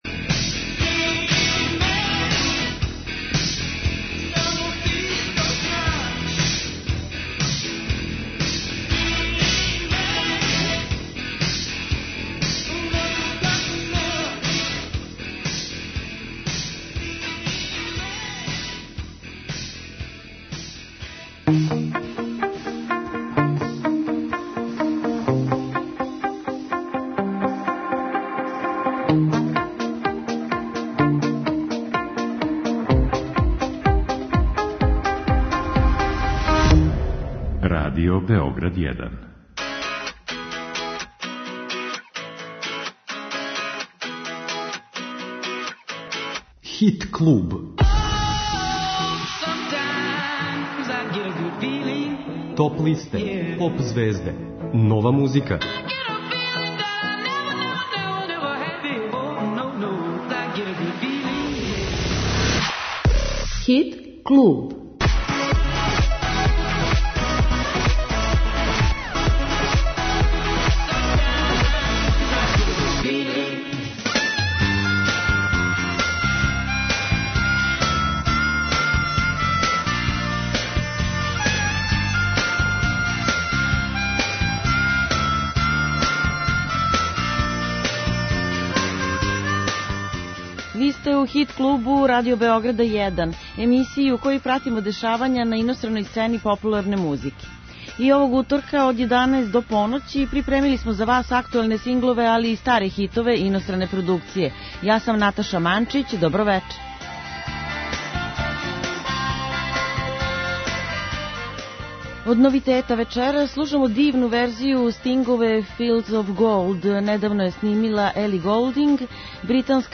иностране поп музике